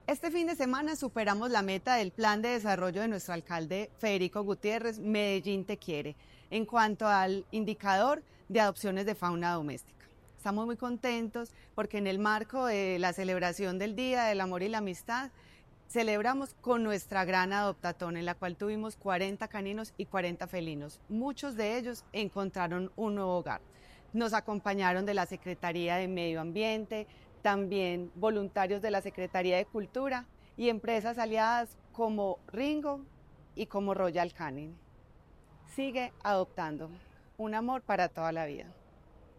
Declaraciones subsecretaria de Protección y Bienestar Animal, Elizabeth Coral Duque
Declaraciones-subsecretaria-de-Proteccion-y-Bienestar-Animal-Elizabeth-Coral-Duque.mp3